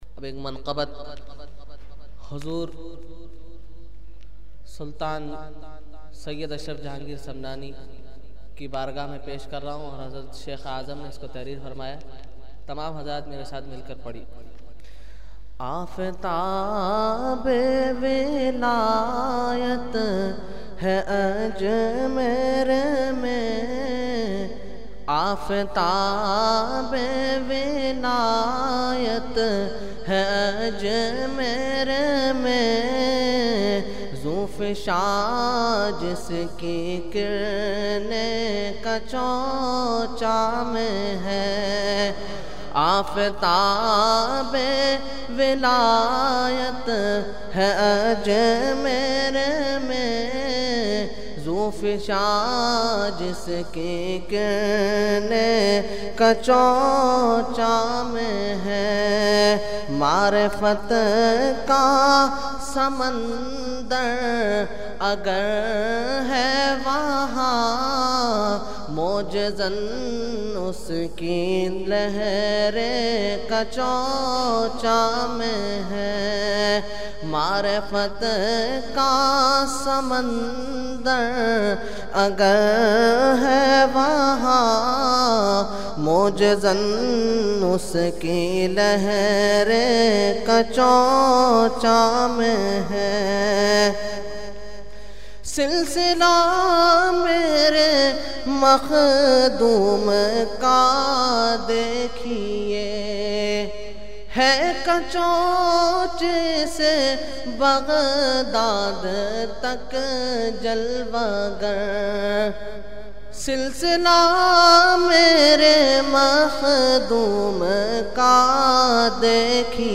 Manqabat